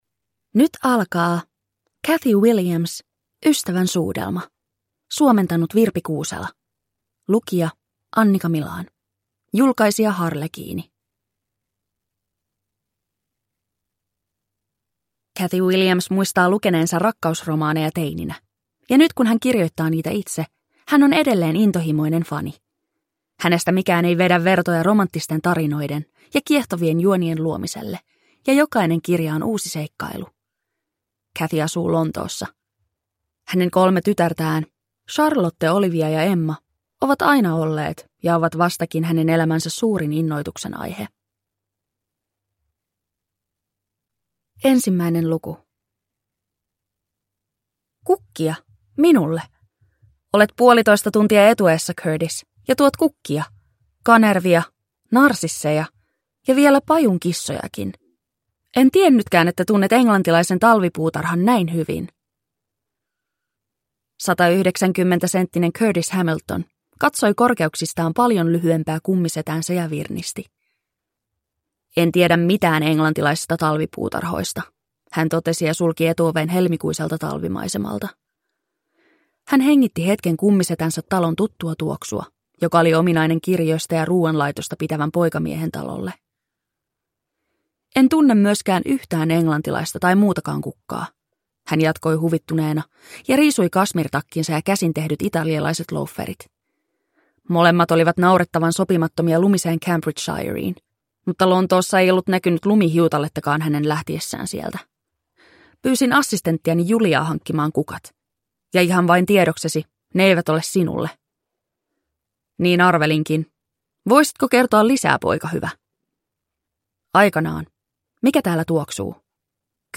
Ystävän suudelma – Ljudbok